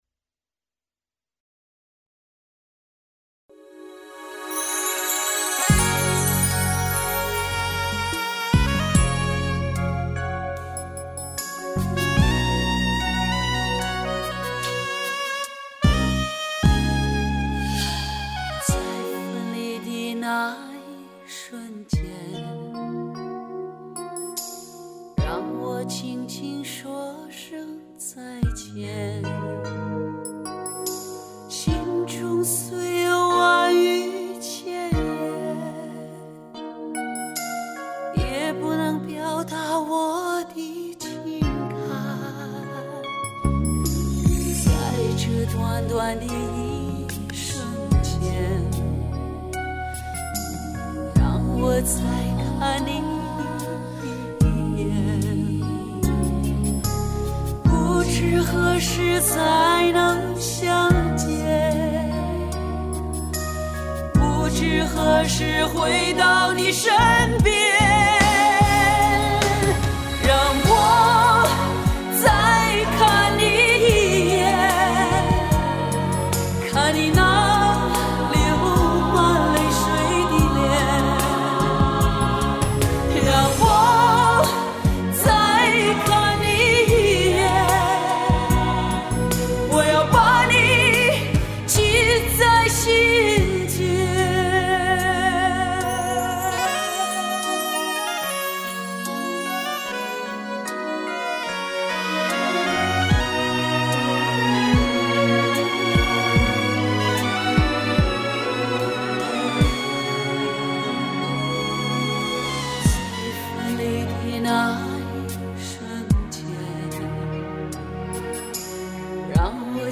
新乐伴心声恍若天籁